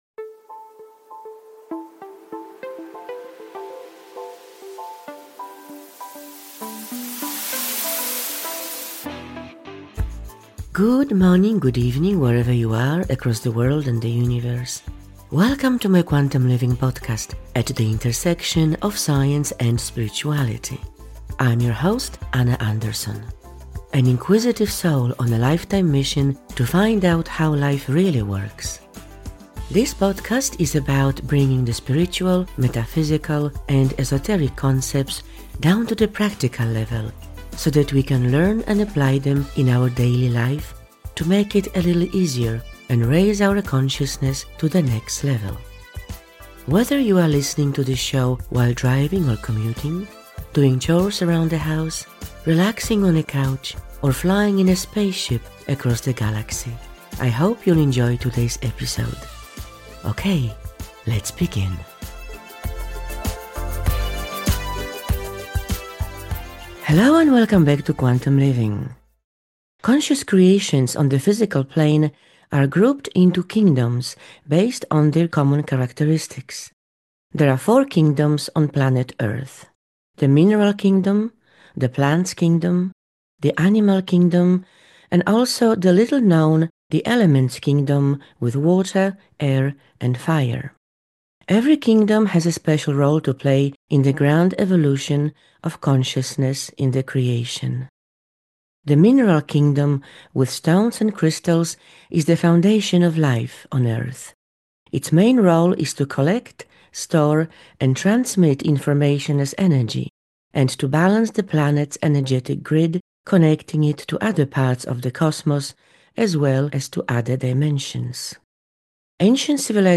In a nutshell – this is a high level yet very accessible conversation.